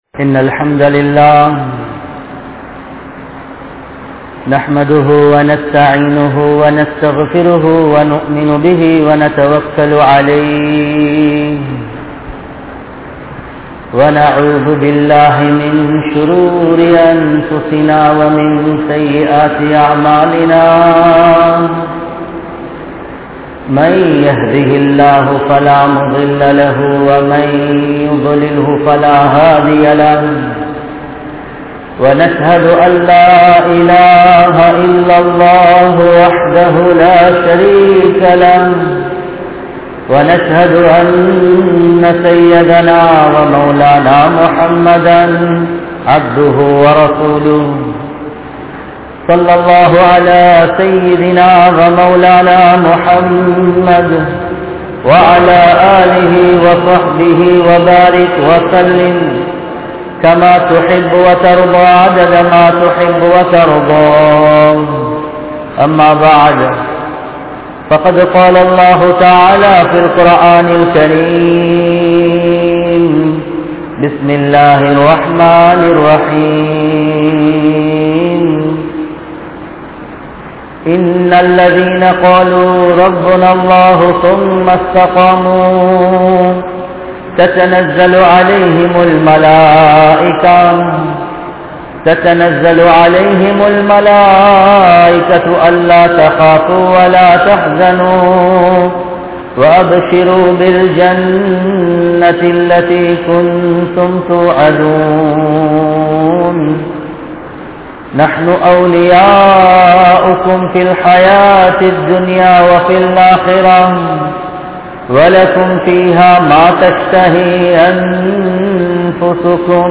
Ungaluku Paavaththin Meethu Inpama? | Audio Bayans | All Ceylon Muslim Youth Community | Addalaichenai